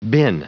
Prononciation du mot bin en anglais (fichier audio)
Prononciation du mot : bin